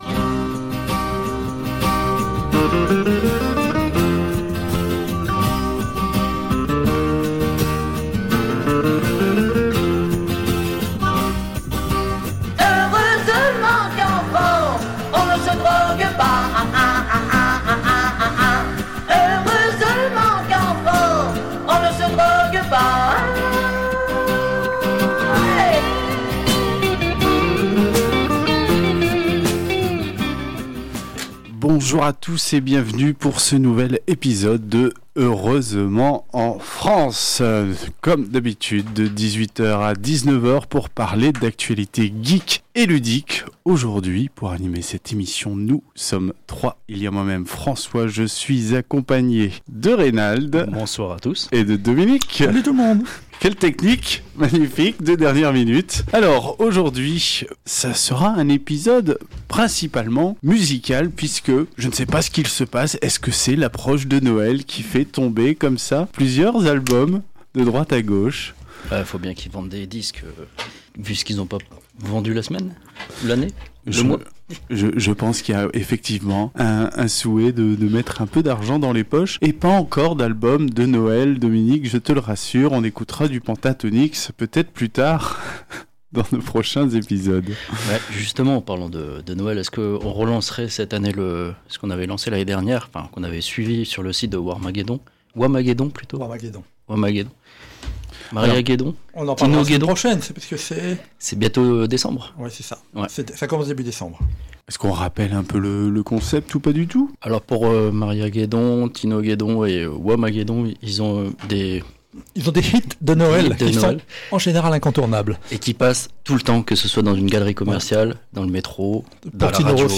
Au sommaire de cet épisode diffusé le 21 novembre 2021 sur Radio Campus 106.6 :